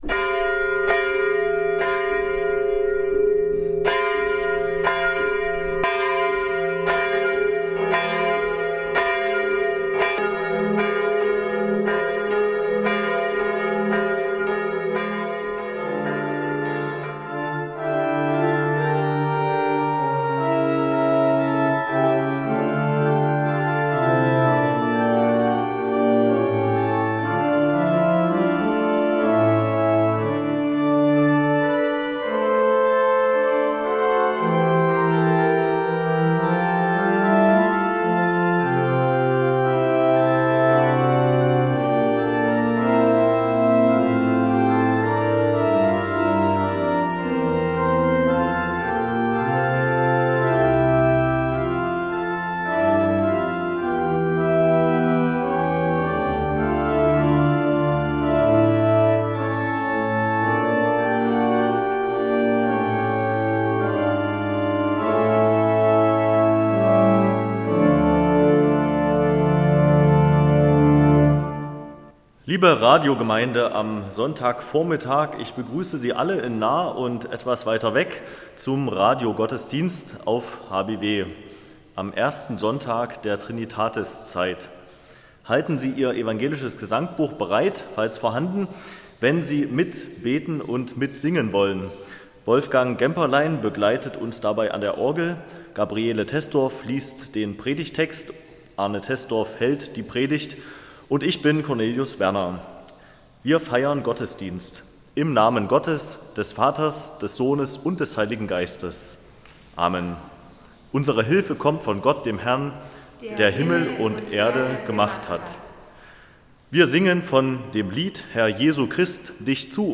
Radiogottesdienst
Deshalb bringen wir Ihnen den Gottesdienst einmal im Monat weiterhin nach Hause in die gute Stube. Der Evangelische Kirchenkreis Bernburg hat wieder einen Radiogottesdienst für Sie aufgezeichnet.